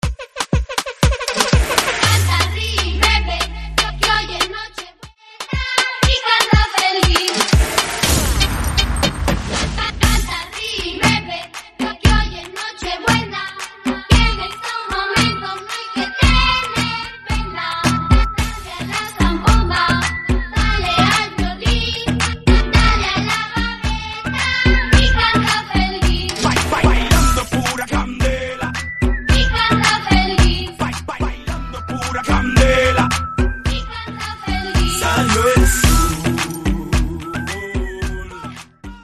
Dembow
Navidad , Transición